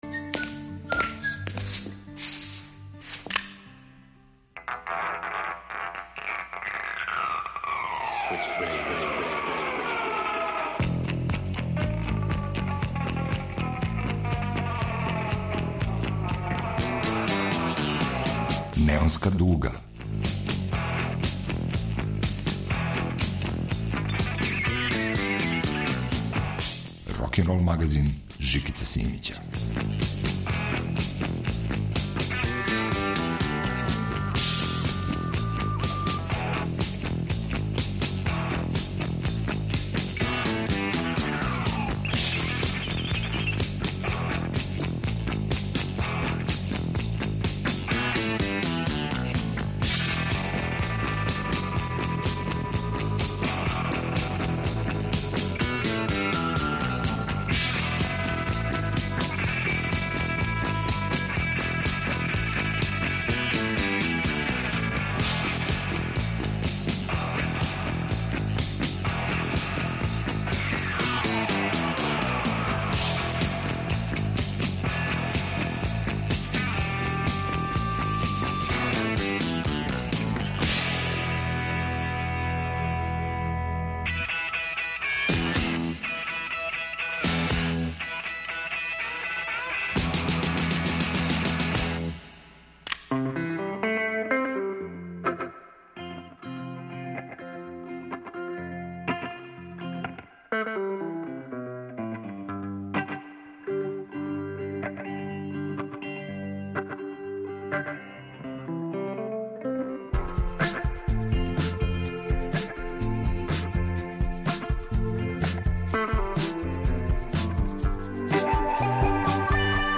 рокенрол магазин
Вратоломни сурф кроз време и жанрове.